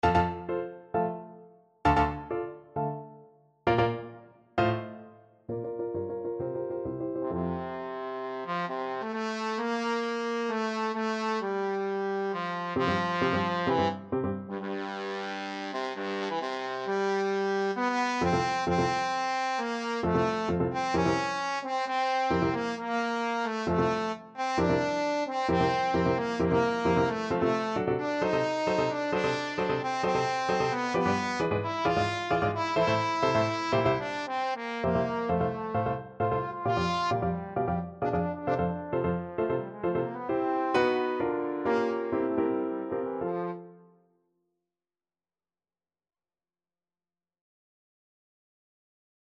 Trombone
2/2 (View more 2/2 Music)
F major (Sounding Pitch) (View more F major Music for Trombone )
Allegro agitato e appassionato assai = 132 (View more music marked Allegro)
G3-F5
Classical (View more Classical Trombone Music)